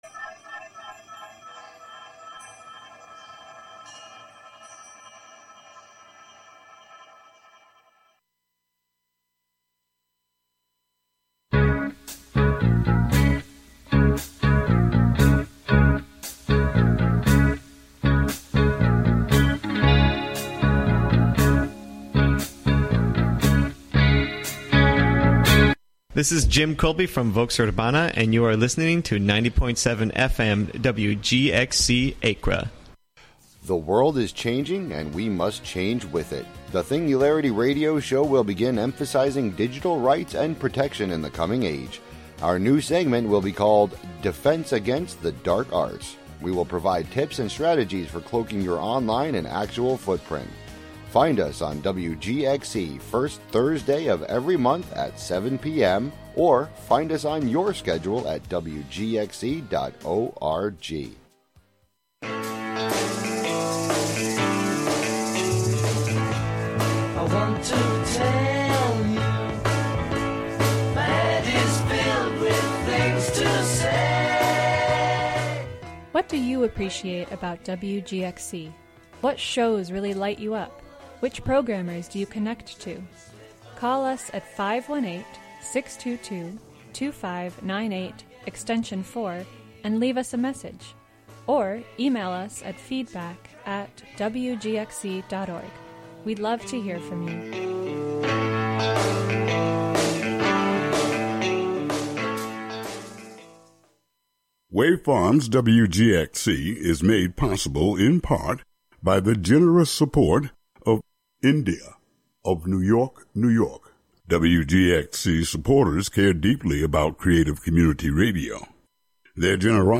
In-studio / pedestrian interviews, local event listings, call-ins, live music, and other chance connections will be sought on air.